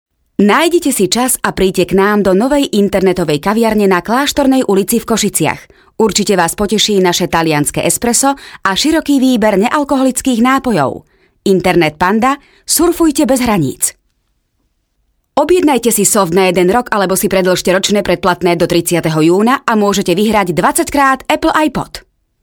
Sprecherin slowakisch für TV / Rundfunk / Industrie.
Sprechprobe: Industrie (Muttersprache):
Professionell voice over artist from Slovakia.